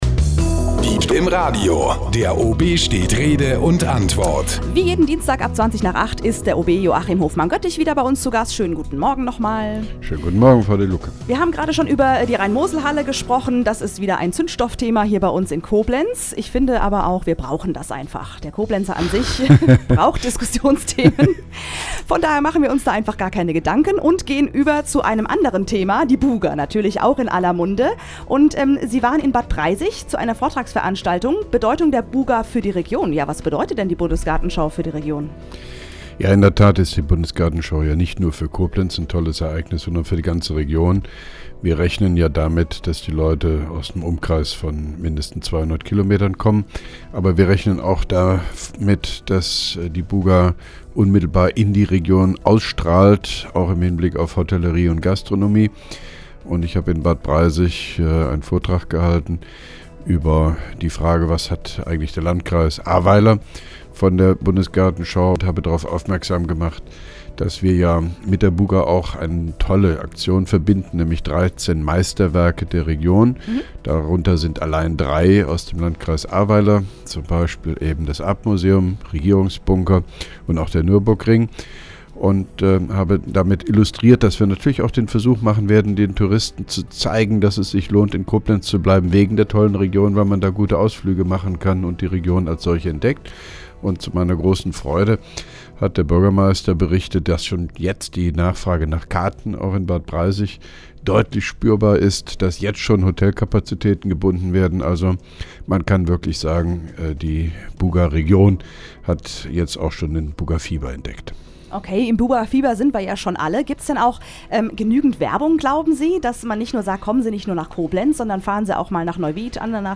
(2) Koblenzer Radio-Bürgersprechstunde mit OB Hofmann-Göttig 25.01.2011